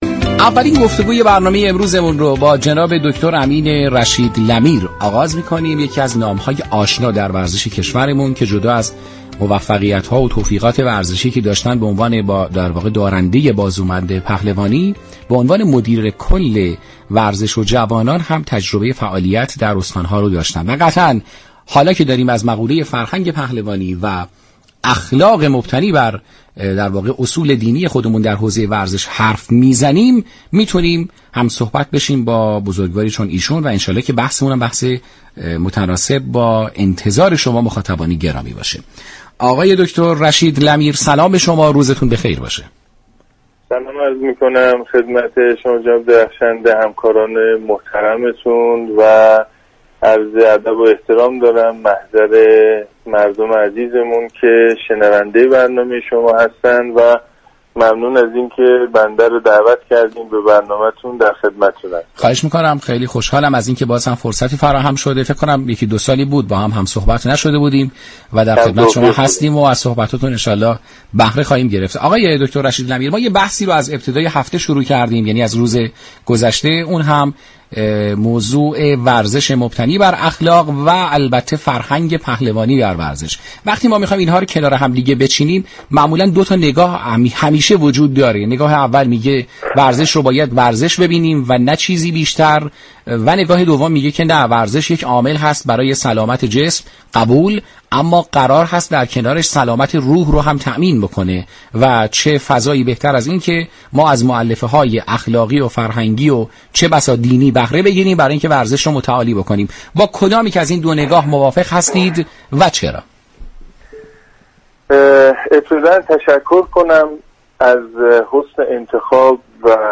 این برنامه زنده ساعت 12 هر روز بجز جمعه ها به مدت 60 دقیقه از شبكه رادیویی ورزش تقدیم شنوندگان می شود.